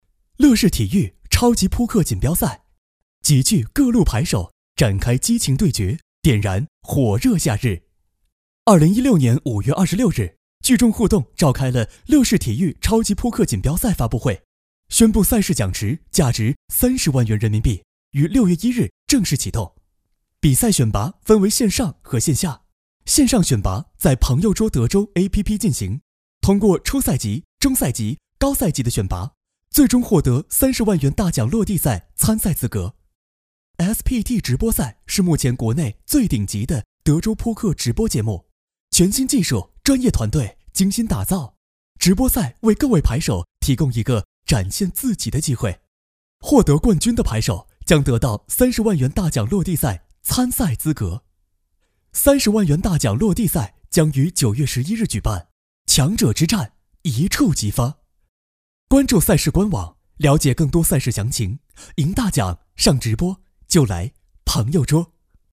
国语青年积极向上 、时尚活力 、男广告 、200元/条男9 国语 男声 彩铃 中英文 积极向上|时尚活力